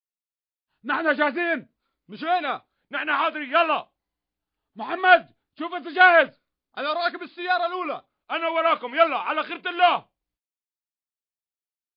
два араба спорят о чем-то